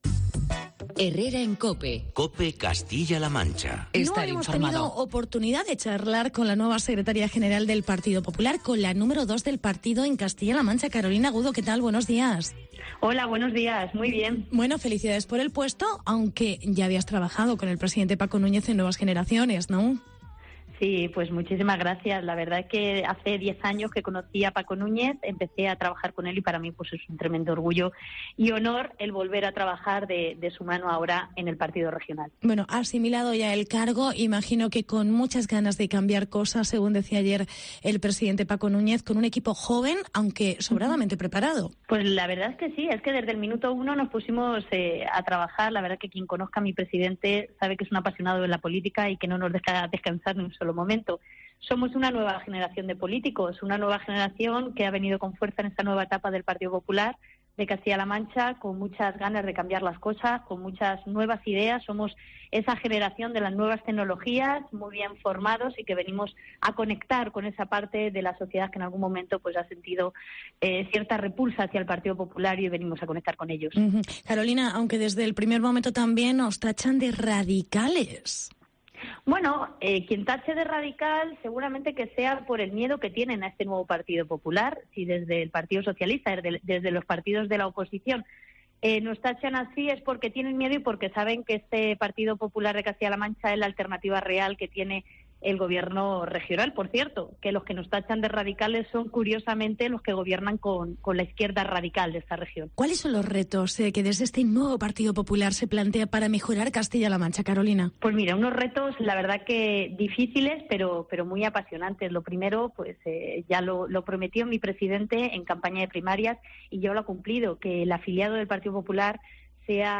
Entrevista con Carolina Agudo. Secretaria General del PP CLM
Madrid - Publicado el 17 oct 2018, 13:37 - Actualizado 15 mar 2023, 16:42